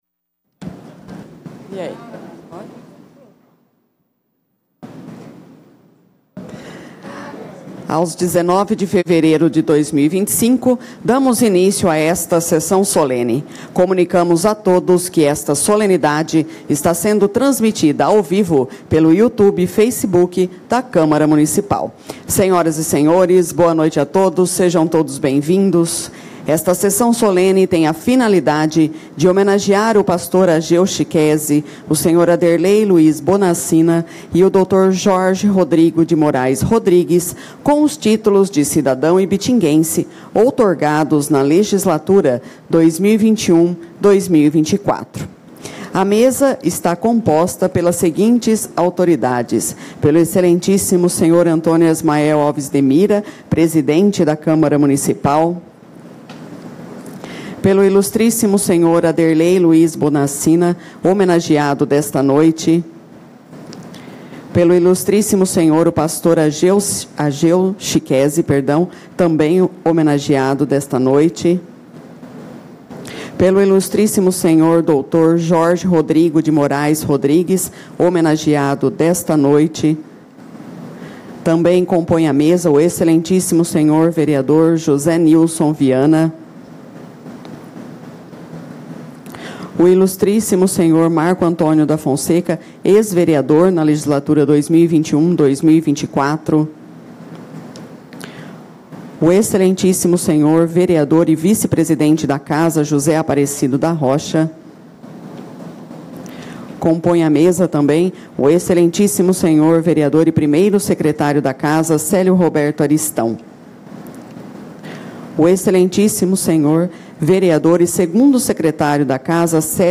Sessão Solene